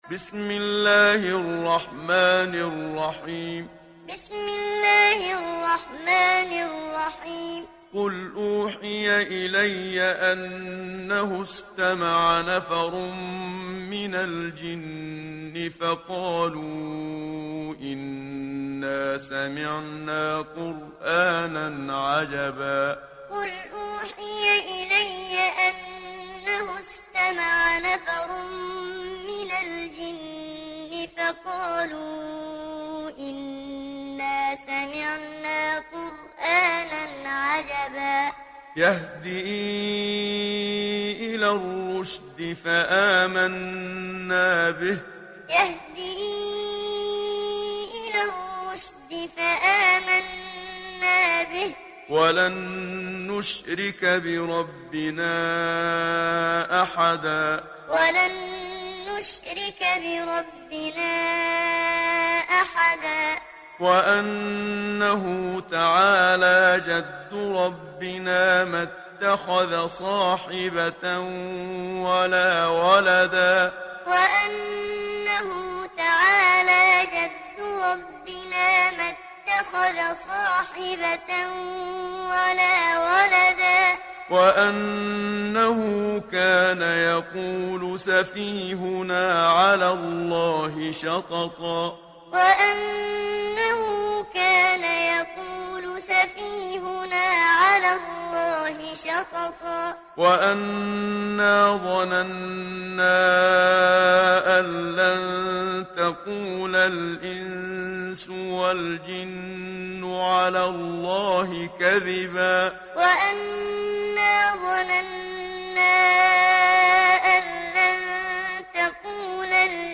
دانلود سوره الجن محمد صديق المنشاوي معلم